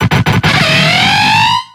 Audio / SE / Cries / YANMEGA.ogg